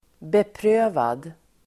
Uttal: [bepr'ö:vad]